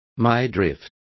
Complete with pronunciation of the translation of midriff.